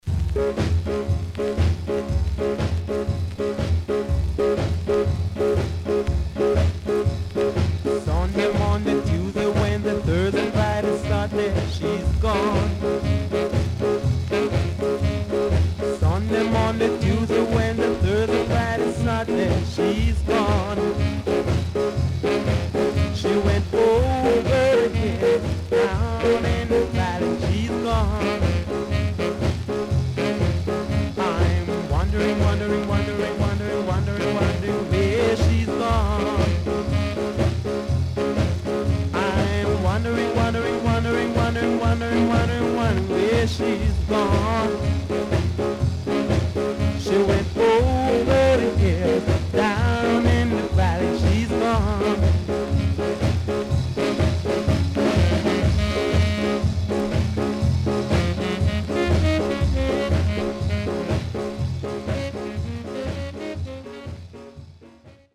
W-Side Good Ska Vocal
SIDE A:ヒスノイズ入ります。